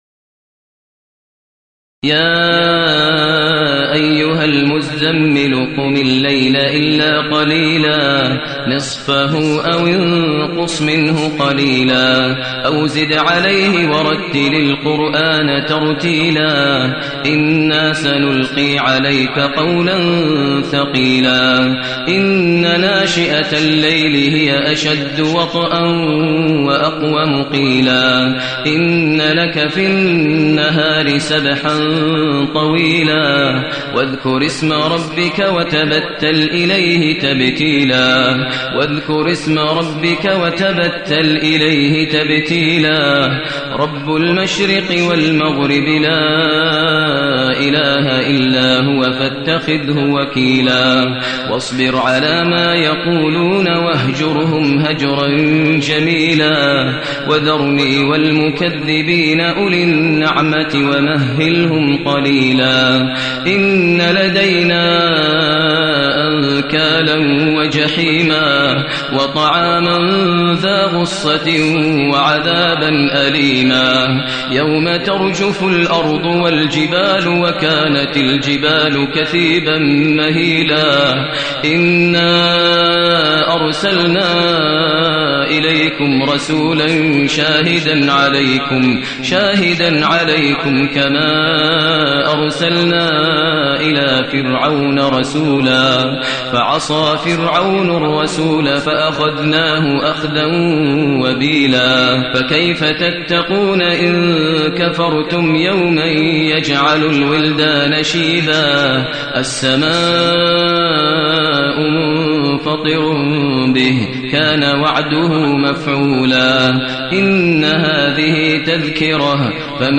المكان: المسجد النبوي الشيخ: فضيلة الشيخ ماهر المعيقلي فضيلة الشيخ ماهر المعيقلي المزمل